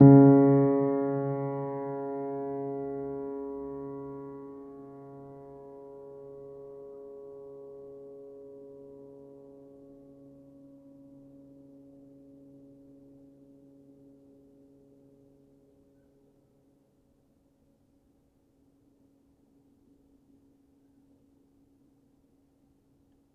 healing-soundscapes/Sound Banks/HSS_OP_Pack/Upright Piano/Player_dyn2_rr1_014.wav at main